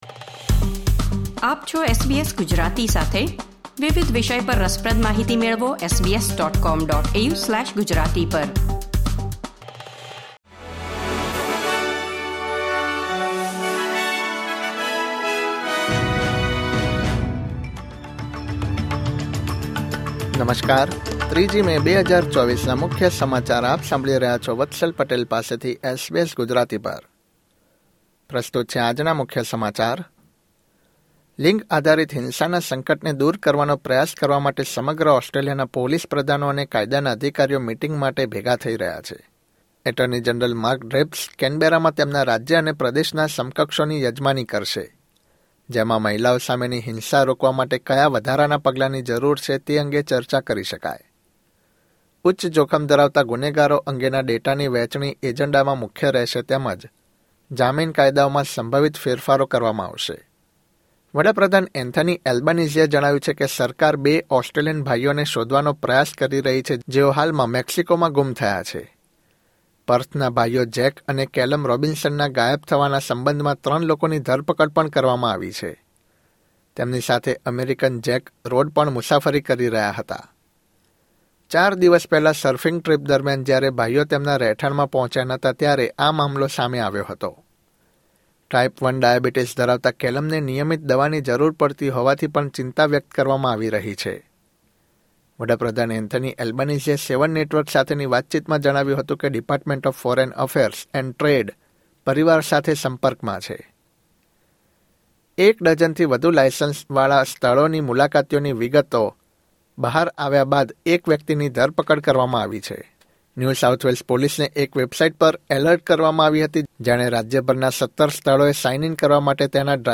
SBS Gujarati News Bulletin 3 May 2024